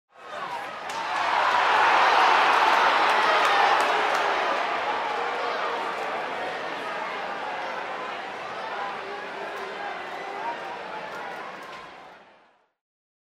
Звук недовольной толпы
Негодование сборища людей для монтажа.
11. Крики на стадионе недовольных фанатов
zvuk-nedovolnoi-tolpy-2.mp3